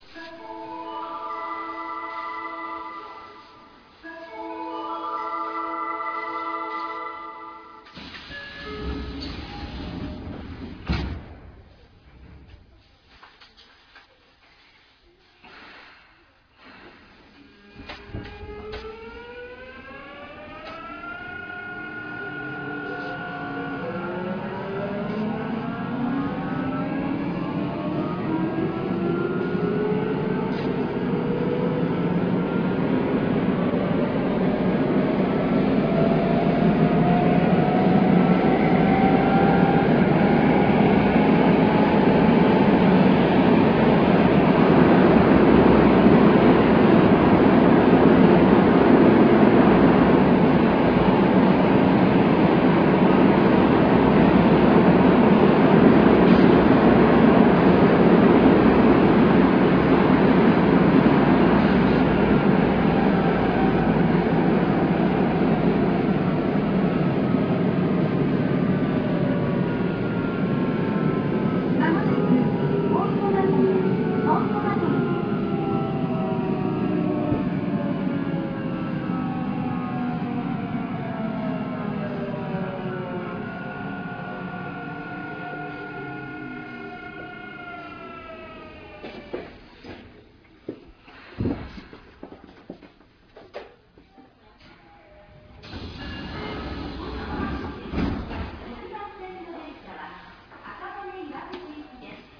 走行音(三菱GTOその1)[sub90gma.ra/RealAudio3.0-28.8 Mono, full response/199KB]
録音区間：南北線東大前→本駒込
種類：VVVFインバータ制御(三菱GTO第2世代Bタイプ、1C4M×2群/2両)